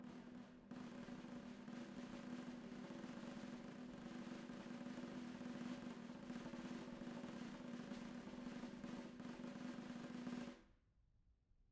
Snare2-rollSN_v1_rr1_Sum.wav